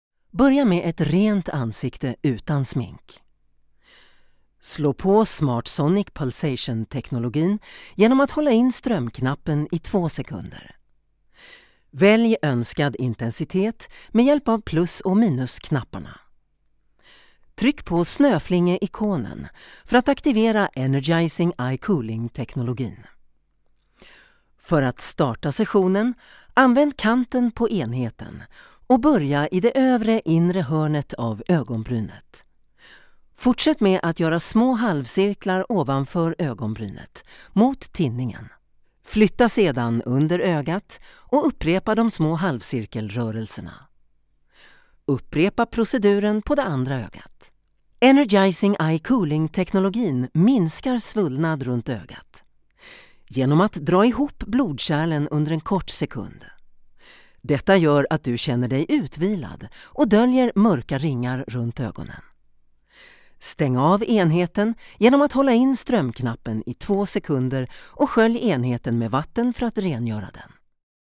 Tief, Zugänglich, Erwachsene, Warm
Erklärvideo